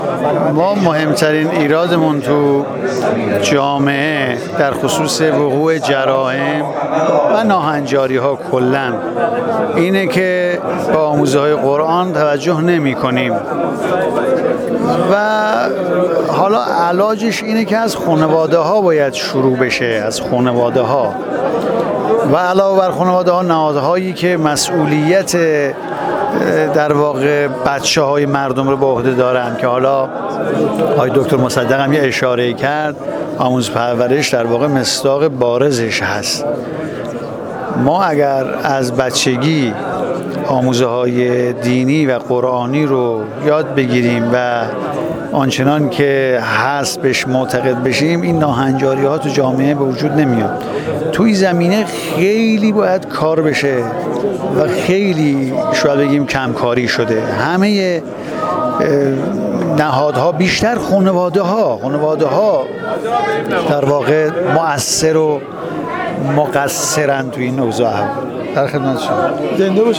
وزیر دادگستری در گفت‌وگو با ایکنا: